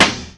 Shady_Snare_6.wav